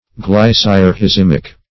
Search Result for " glycyrrhizimic" : The Collaborative International Dictionary of English v.0.48: Glycyrrhizimic \Glyc`yr*rhi*zim"ic\, a. (Chem.)